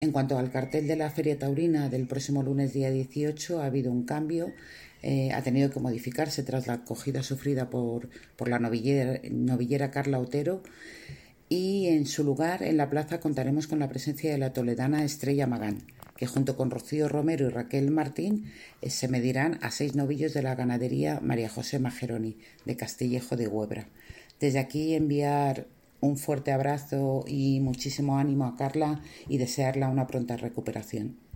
Declaraciones de la concejala Charo Martín 2